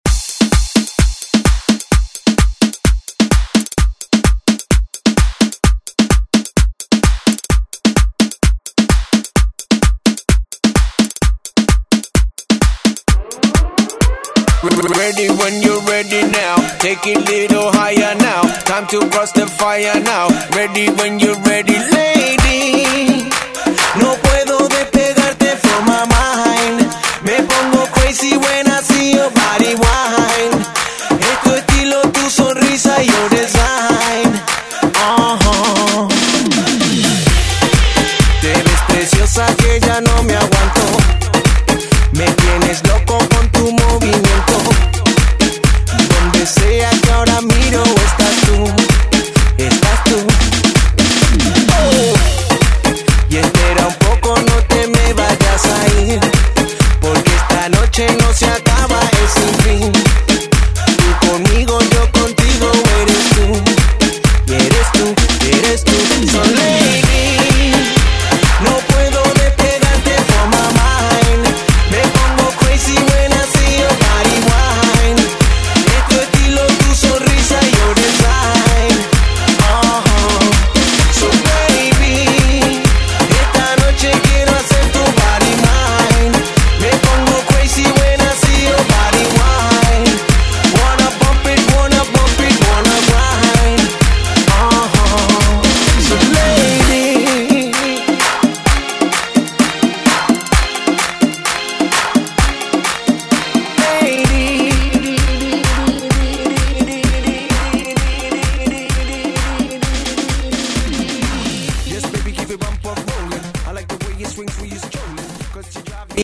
Throwback Hip Hop RnB Rap Music
Extended Intro Outro
100 bpm
2000's , HIPHOP , R & B Version